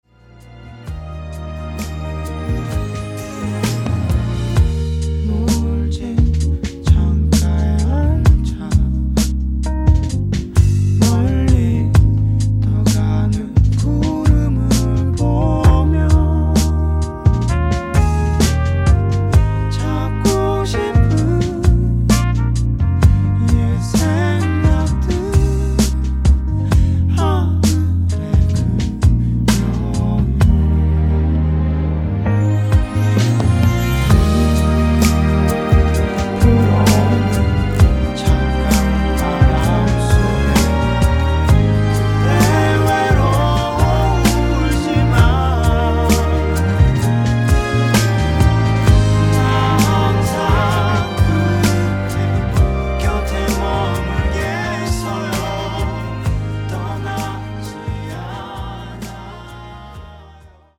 음정 원키 3:46
장르 가요 구분 Voice MR